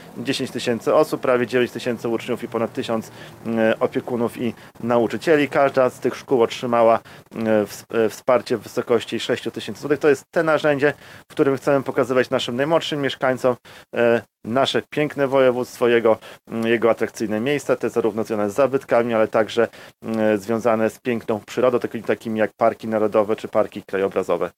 Łukasz Prokorym – marszałek województwa podlaskiego.